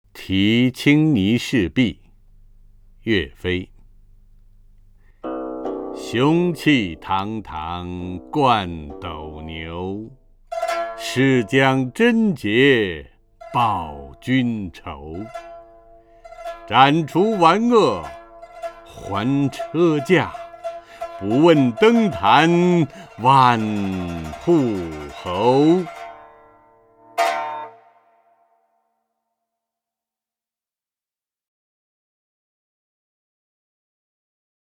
陈铎朗诵：《题青泥市壁》(（南宋）岳飞) （南宋）岳飞 名家朗诵欣赏陈铎 语文PLUS